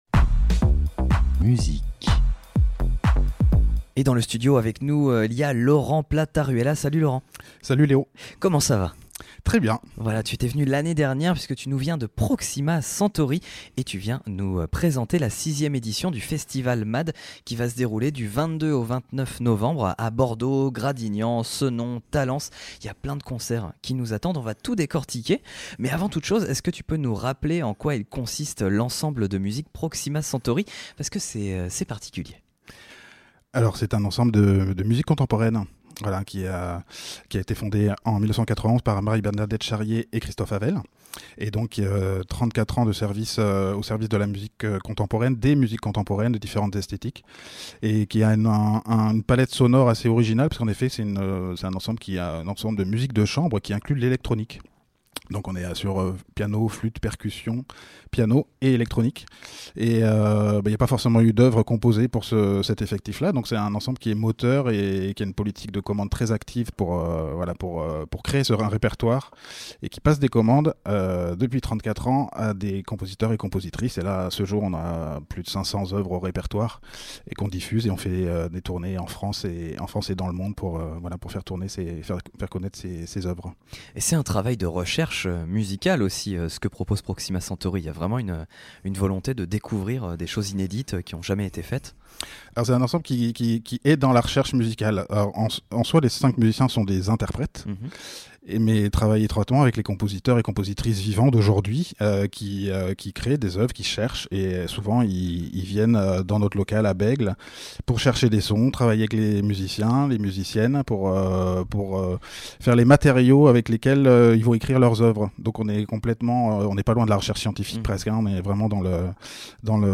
Radio-Campus-Bordeaux-Passages-Interview-Proxima-Centauri-presente-le-festival-MaD.mp3